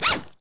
foley_zip_zipper_short_06.wav